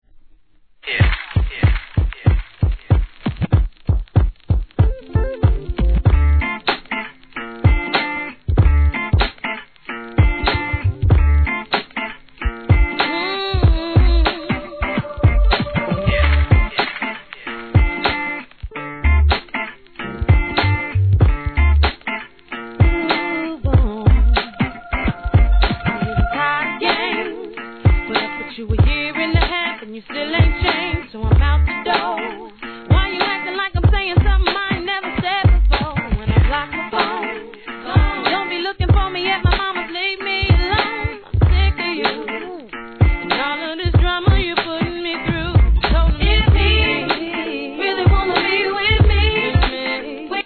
HIP HOP/R&B
お洒落なサウンドとバランスのとれたヴォーカル＆RAPでハイ・クオリィティー♪